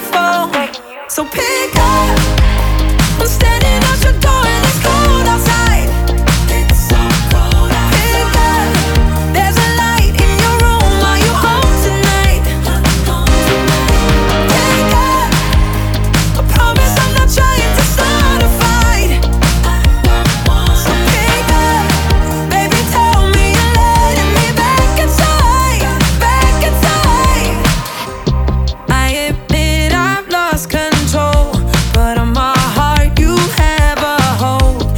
2025-05-30 Жанр: Поп музыка Длительность